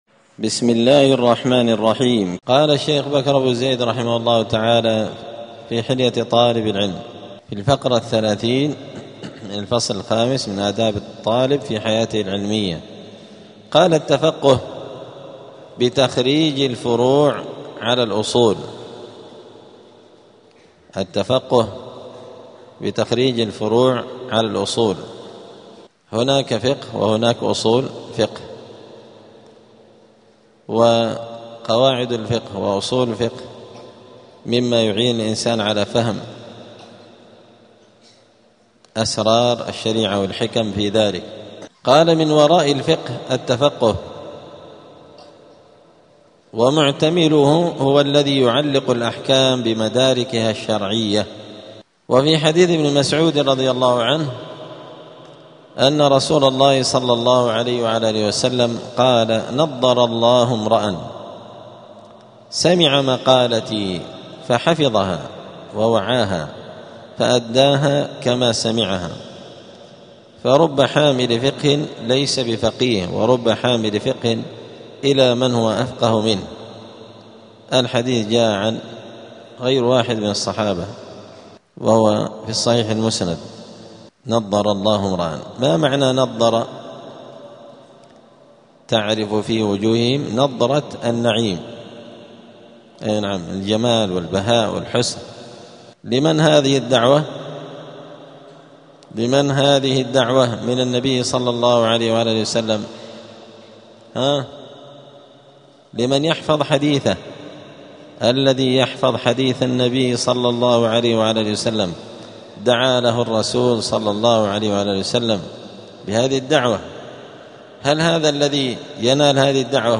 الخميس 27 جمادى الآخرة 1447 هــــ | الدروس، حلية طالب العلم، دروس الآداب | شارك بتعليقك | 7 المشاهدات
دار الحديث السلفية بمسجد الفرقان قشن المهرة اليمن